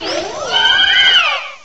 cry_not_florges.aif